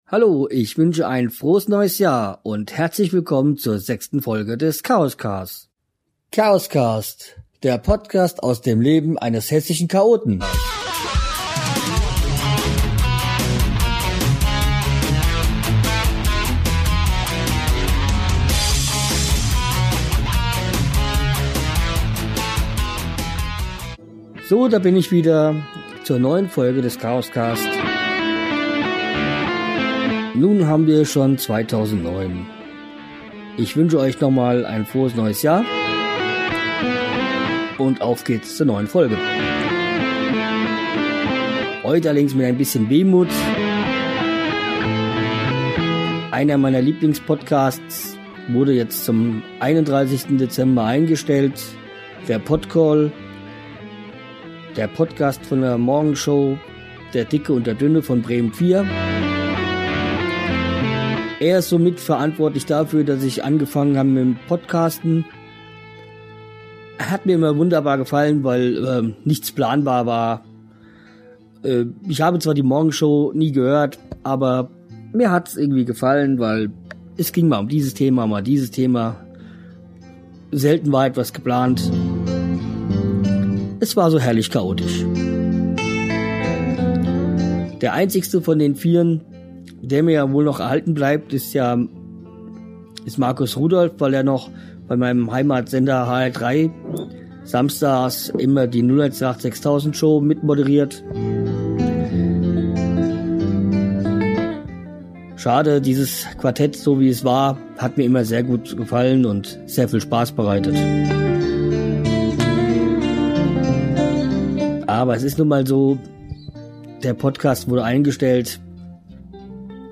Die Musik wurde wie i mmer auf Jamendo gefunden und stammt von